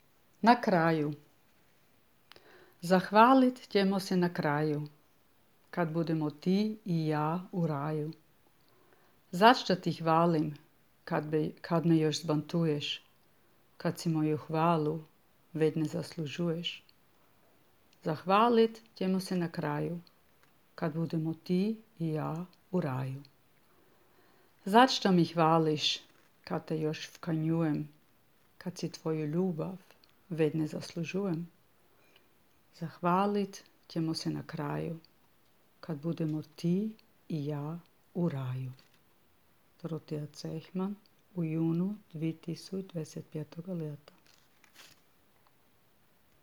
čita